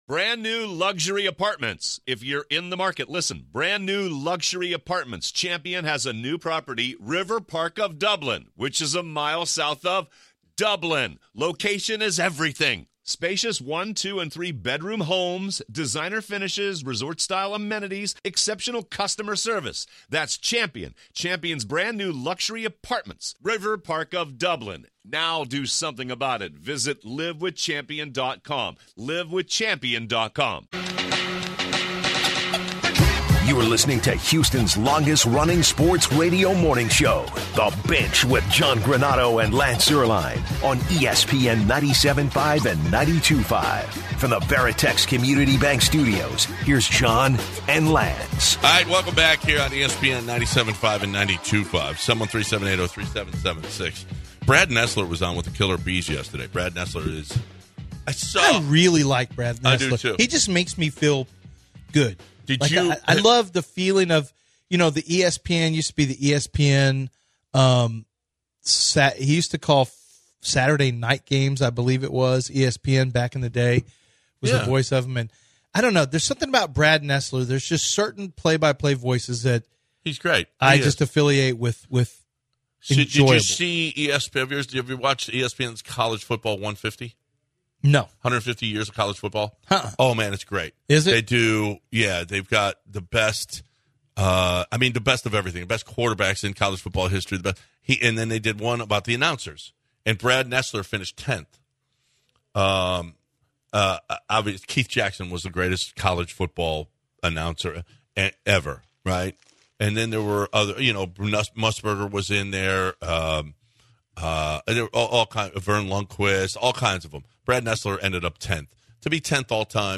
They take some calls from people to discuss more on the College Football Playoff and discuss the transfer portal in college football and some of the top transfers to wrap up the second hour.